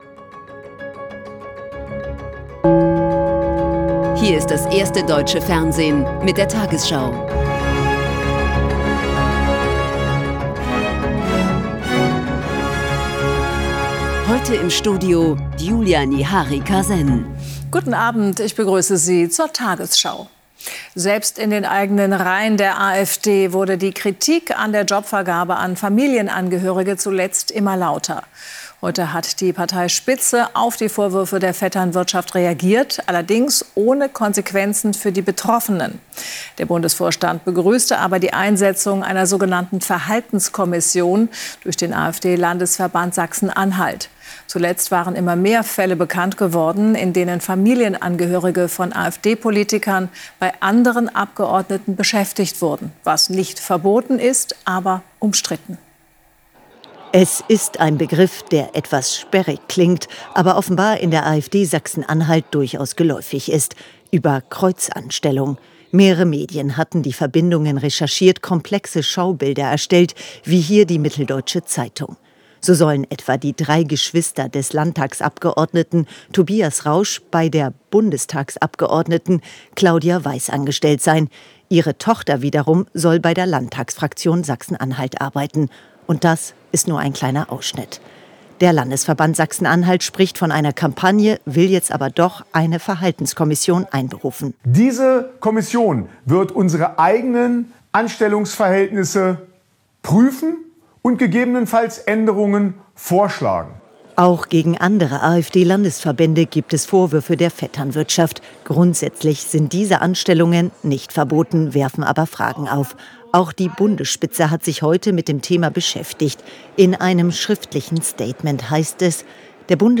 Die 20 Uhr Nachrichten